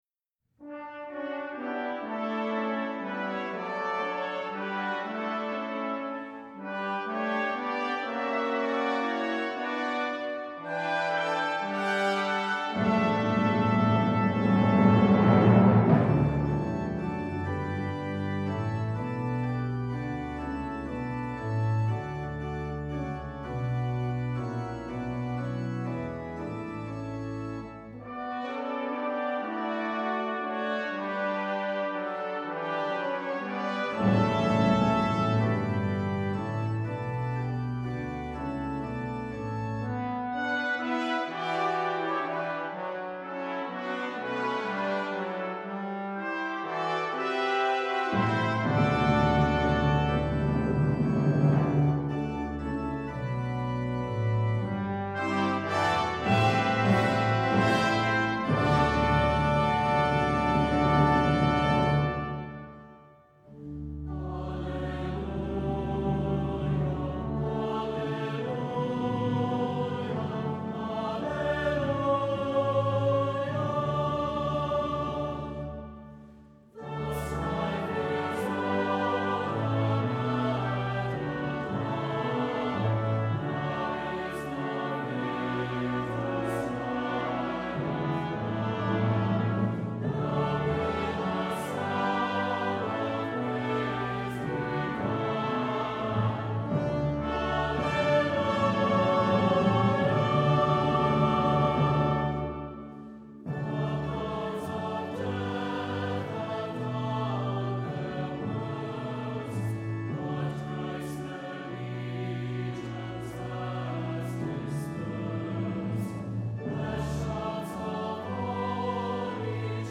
Voicing: SATB, Optional Congregation